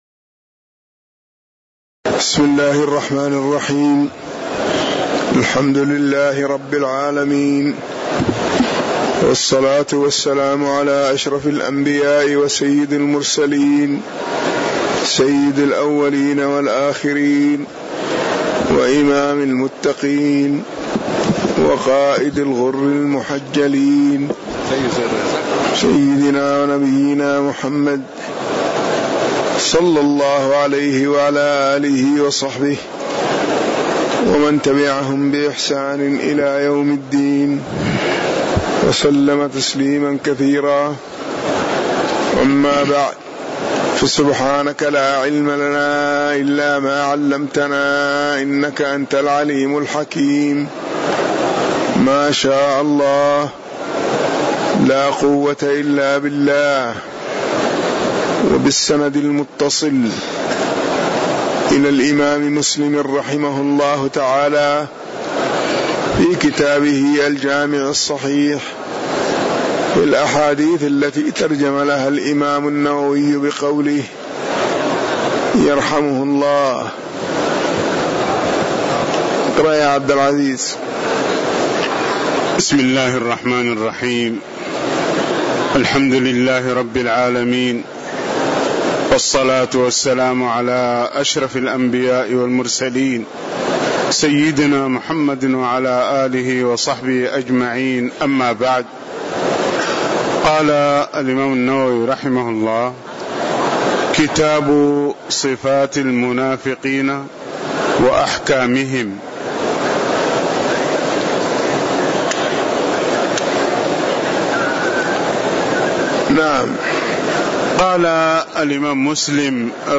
تاريخ النشر ٢٩ جمادى الأولى ١٤٣٨ هـ المكان: المسجد النبوي الشيخ